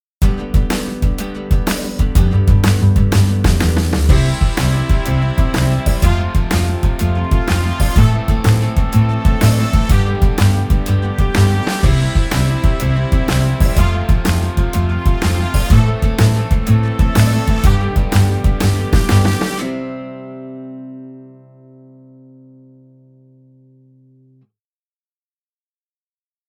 著作権フリーBGM 無料音楽素材
【30秒ほど、明るい、元気、朝、スタート、爽やか、楽しい、ワクワクする、短い】イメージのオリジナルフリーBGMです、